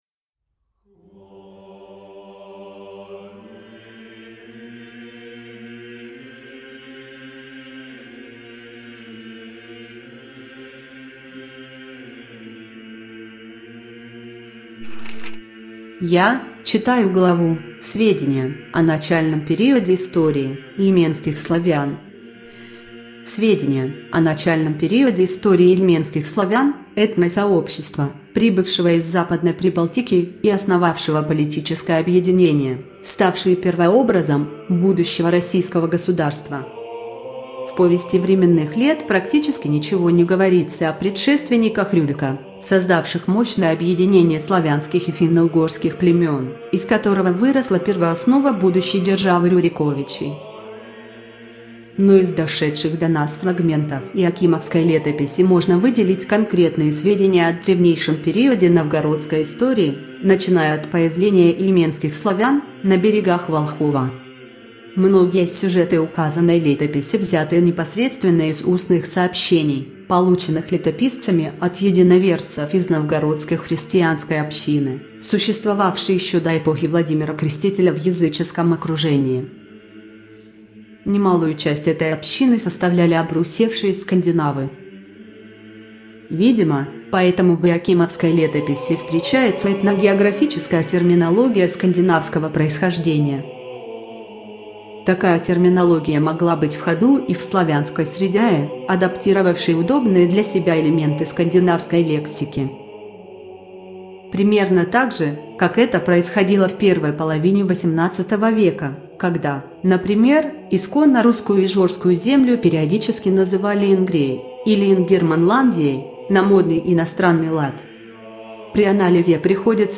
Автор Татищев Василий Никитич из аудиокниги "Иоакимовская летопись".